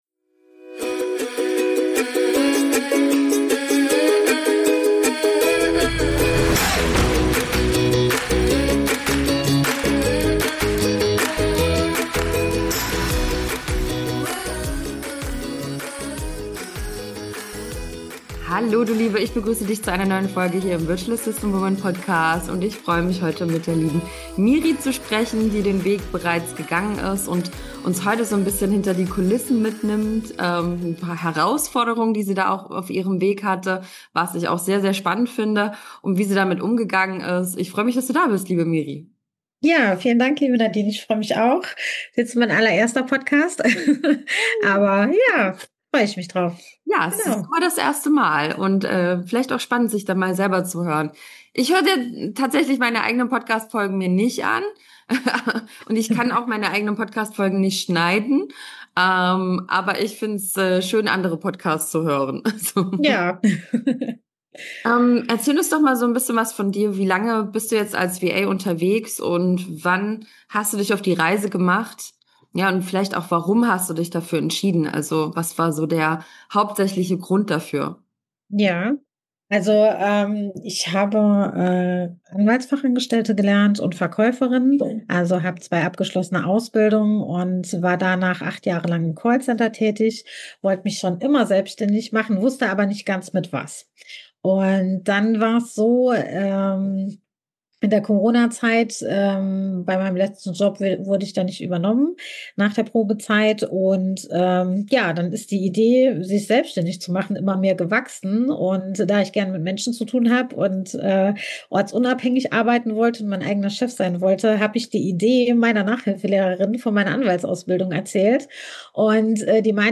Wachsen trotz Selbstzweifel als Virtuelle Assistentin - Interview